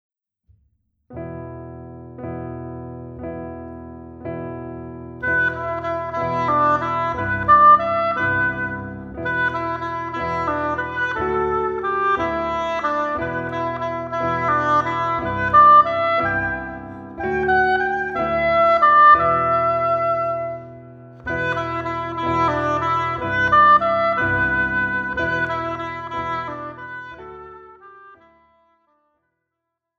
Besetzung: Oboe und Klavier